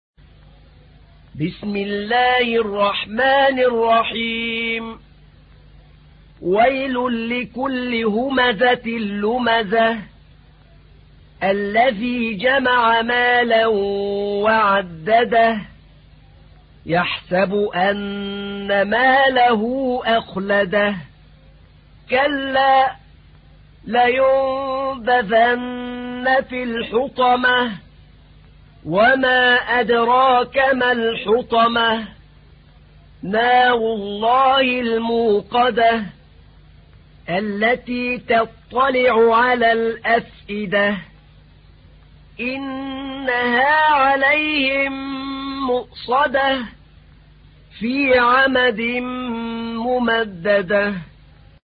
تحميل : 104. سورة الهمزة / القارئ أحمد نعينع / القرآن الكريم / موقع يا حسين